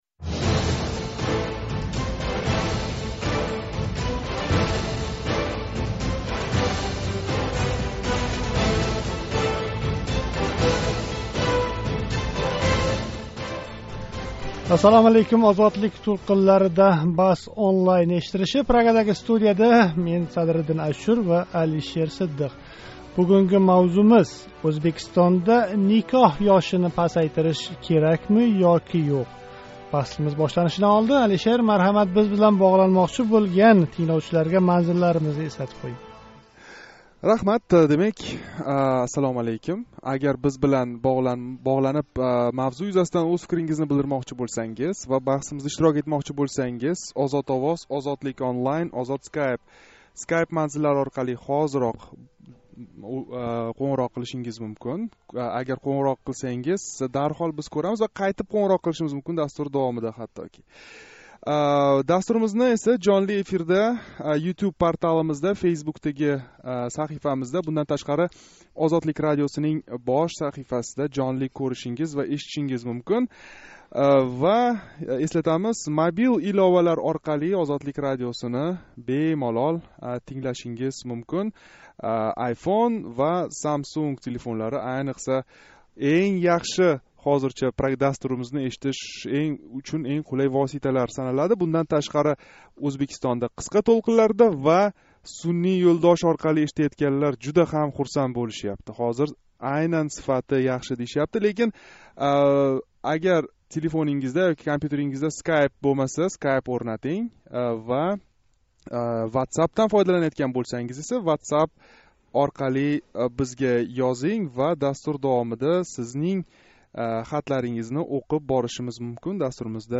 Ўзбекистонда сўнгги пайтларда ёшларнинг эрта никоҳ қуриш ҳолатлари кўп кузатилмоқда. BahsOnlineнинг 3 декабрь, чоршанба куни Тошкент вақти билан соат 19.05да ўтказилган навбатдаги сонида Ўзбекистонда никоҳ ёшини пасайтириш керакми ёки йўқ, деган мавзуда мунозара олиб борилди.